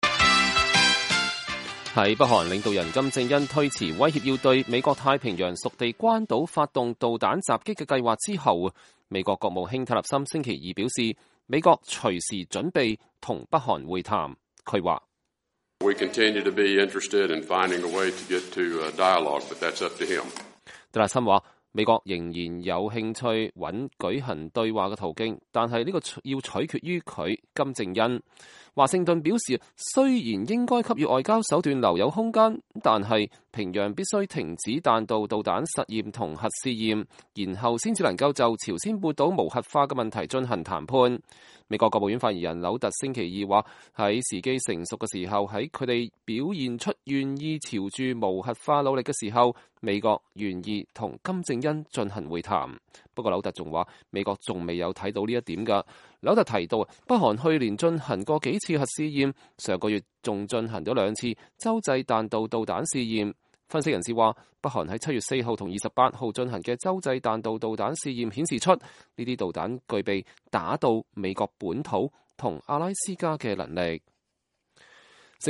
蒂勒森在發表2016年國際宗教自由報告後回答有關北韓的問題 (2017年8月15日)